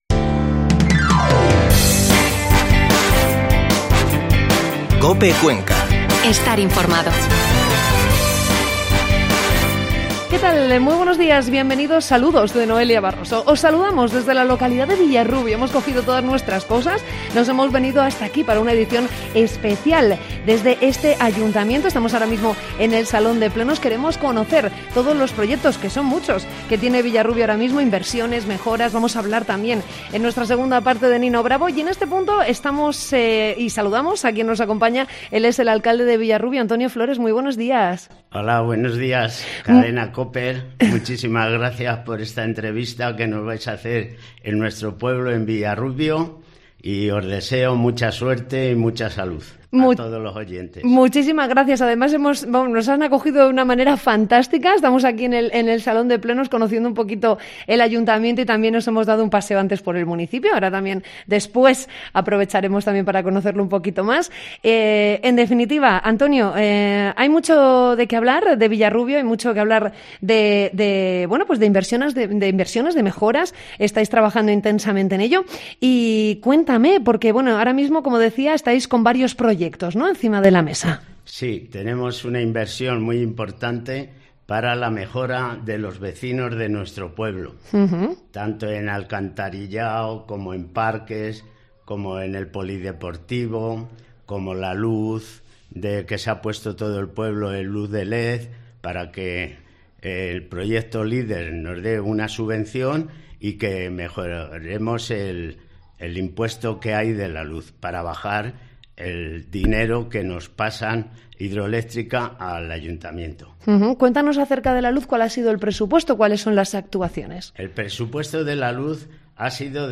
Primera parte de la entrevista con el alcalde de Villarrubio, Antonio Flores